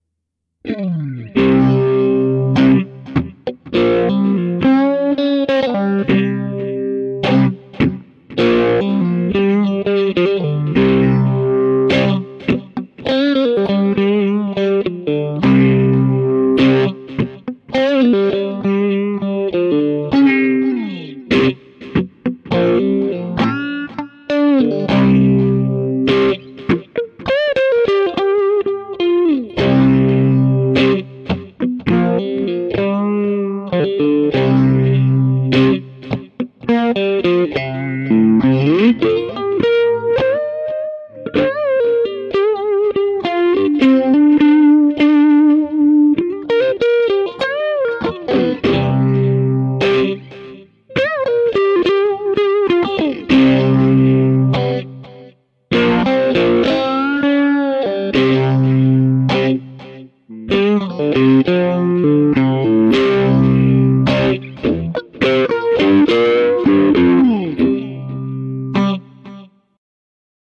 描述：电吉他，清洁，移相器/法兰式声音，有点蓝调但空间宽敞
标签： 蓝色 干净 吉他 法兰 空间 电力 移相器 延迟
声道立体声